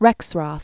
(rĕksrôth), Kenneth 1905-1982.